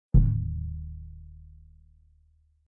Surdo-3.mp3